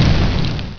pyro_flameoff.wav